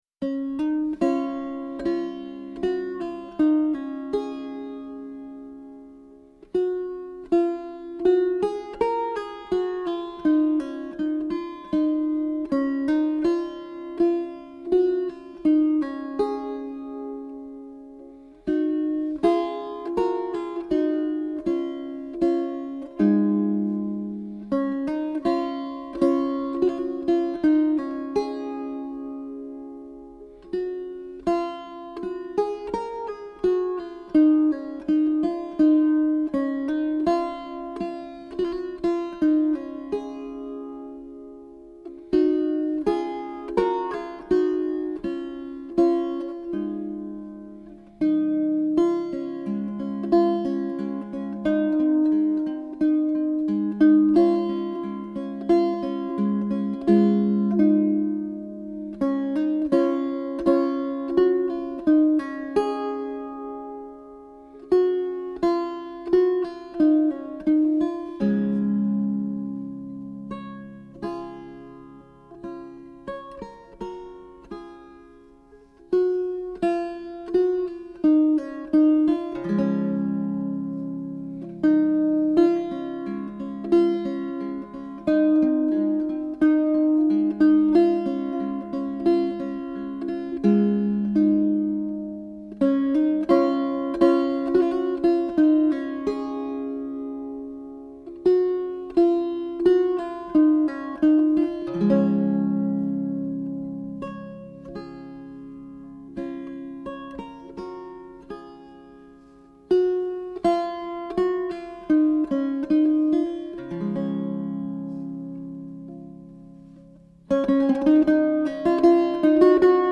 18th-century wire-strung guittar